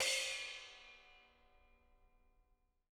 R_B Splash A 02 - Room.wav